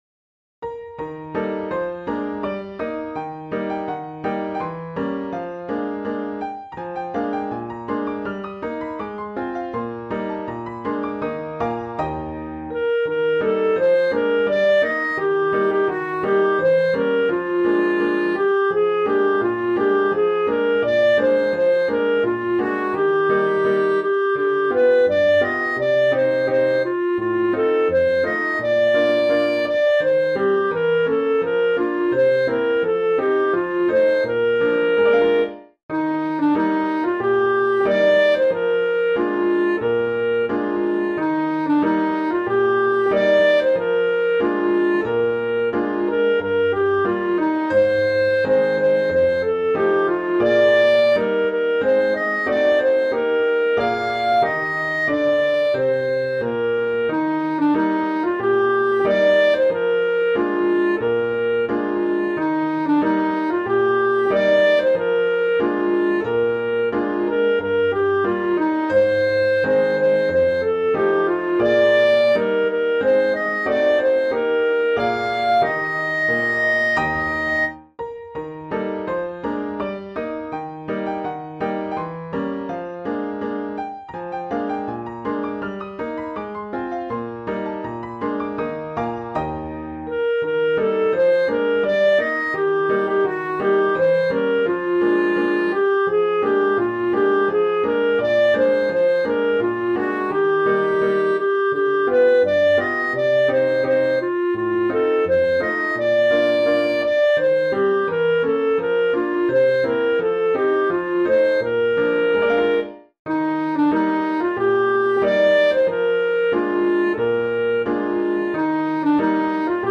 Music Hall Songs: